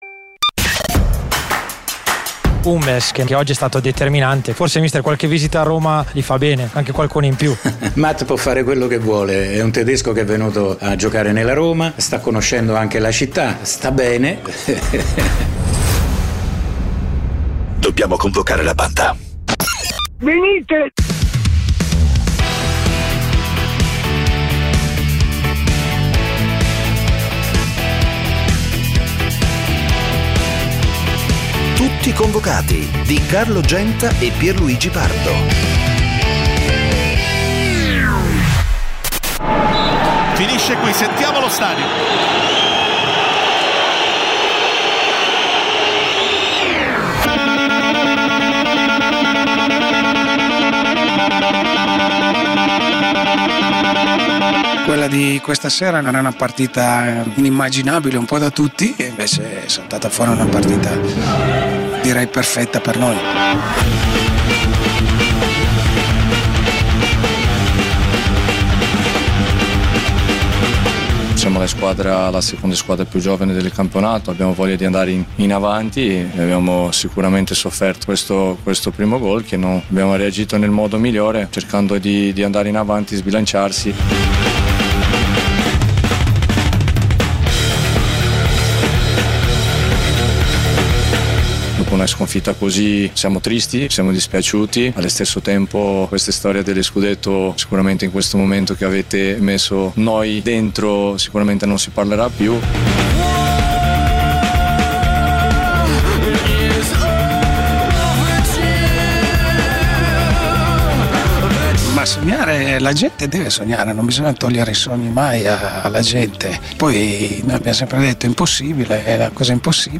Le voci e i suoni del calcio. Una lettura ironica e coinvolgente degli avvenimenti dell'attualità sportiva, senza fanatismi e senza tecnicismi. Commenti, interviste e soprattutto il dibattito con gli ascoltatori, che sono Tutti convocati.
Con imitazioni, tic, smorfie, scherzi da spogliatoio e ironia irriverente. L'unico programma in diretta sul calcio a partite appena finite, in cui da casa o dallo stadio gli ascoltatori possono dire la loro perché sono sempre convocati.